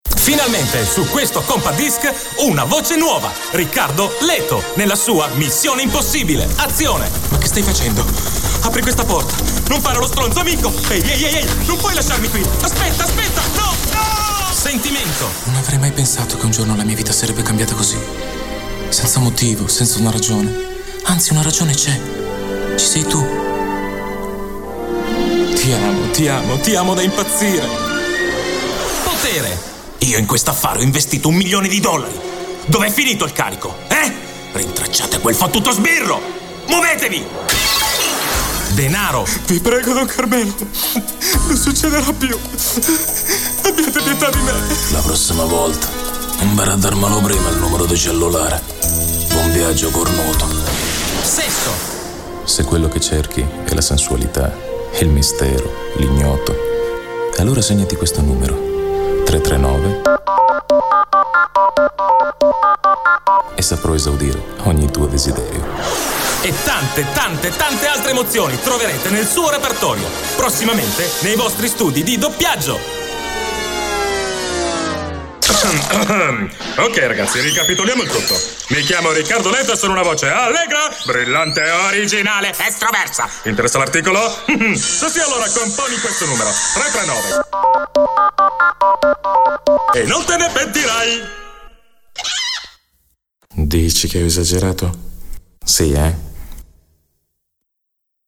Attore, doppiatore speaker
Sprechprobe: Werbung (Muttersprache):